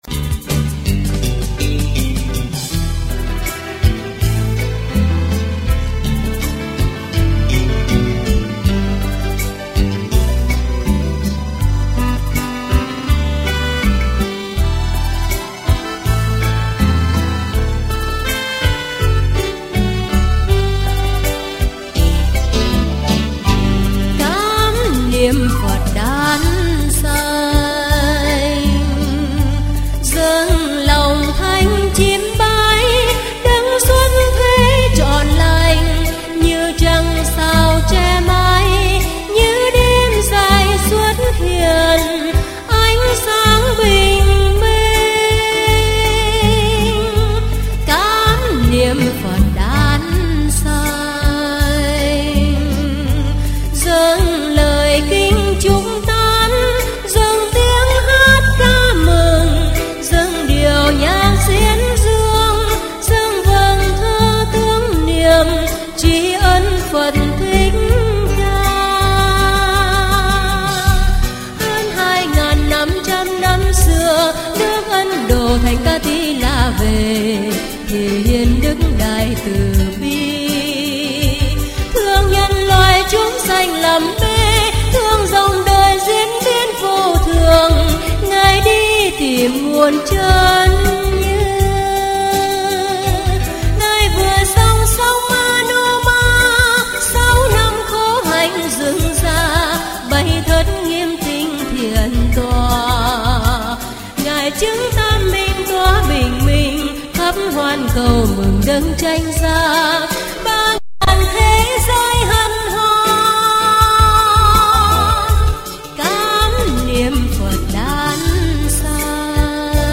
Category: Tân Nhạc